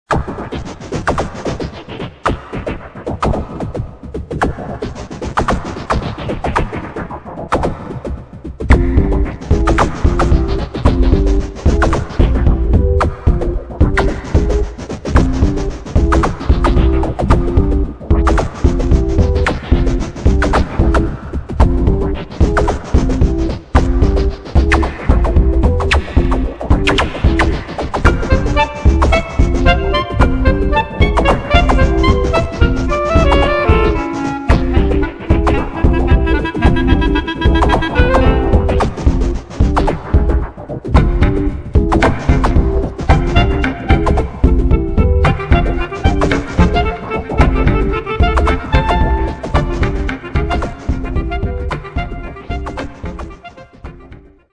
Festival Musiques en Tréfilerie du 8 au 10 février 2006, avec